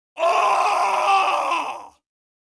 crying-men-sound